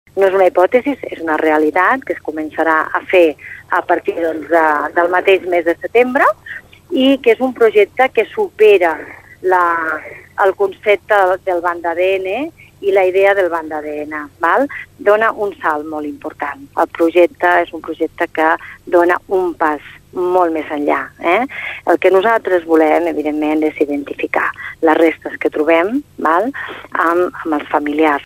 Garcia ha explicat que un dels eixos de l’actuació serà  una geolocalització massiva de possibles fosses a les Terres de l’Ebre.